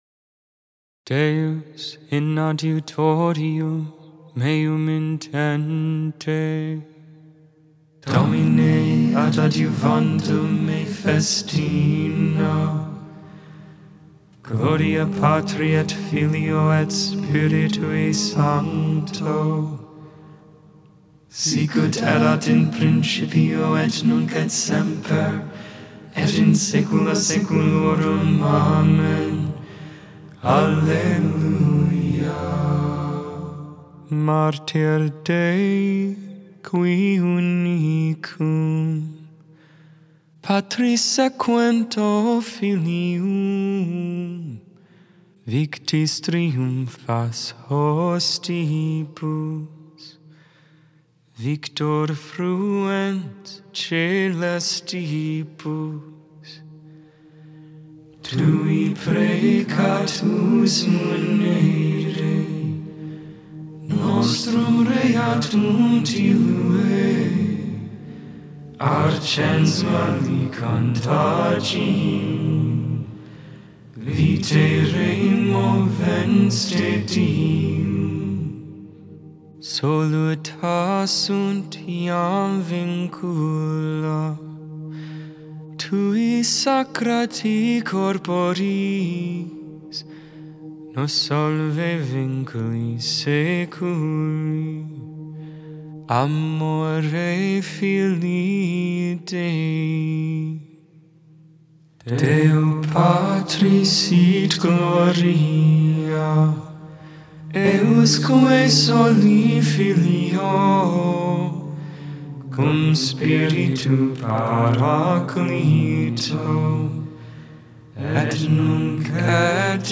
Psalm 108 (Gregorian tone VI)Isaiah 61v10-62v5 (Gregorian tone IV)
(StH adaptation from the Gregorian)Benedictus (English, tone VIII, simple)Intercessions: Hear us, King of eternal Glory.The Lord's Prayer (Simple, Gregorian, adapted for English by StH &c